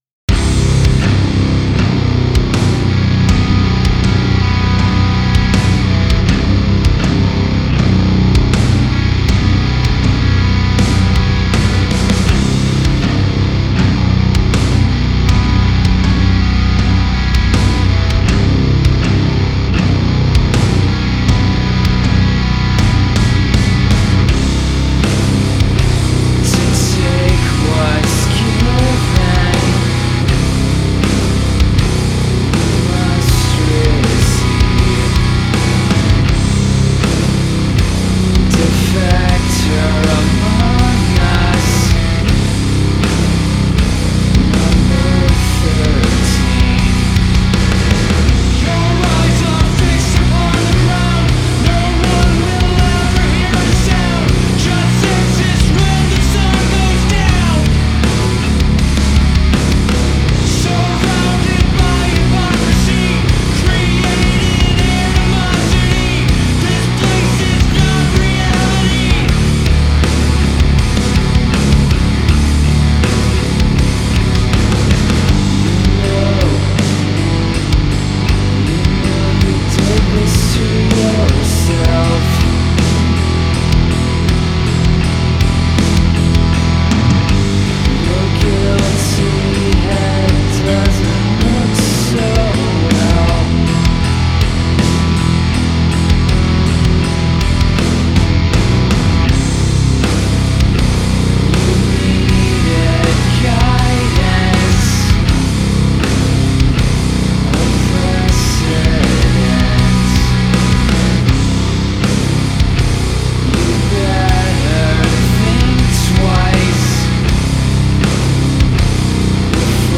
post-metal, grunge, sludge and alternative solo project